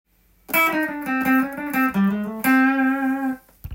混ぜたギターフレーズ集
ブルーノートスケールを弾きながらクロマチックスケールを
混ぜたフレーズです。